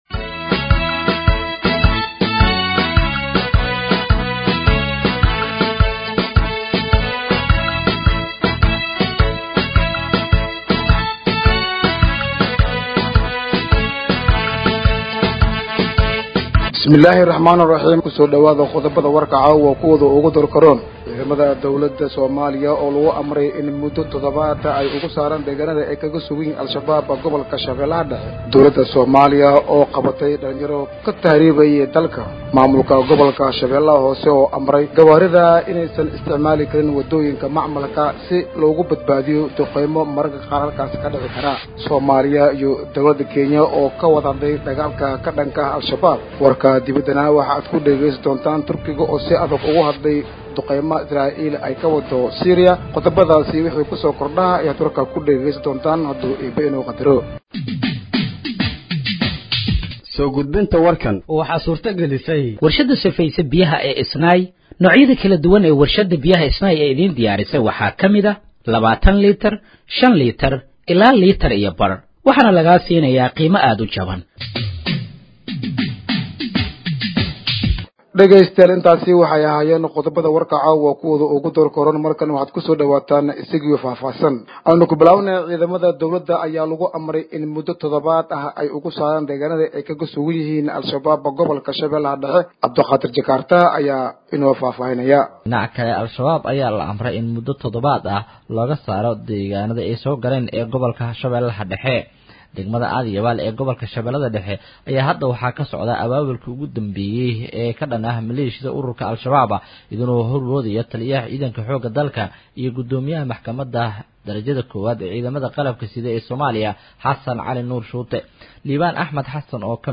Dhageeyso Warka Habeenimo ee Radiojowhar 04/04/2025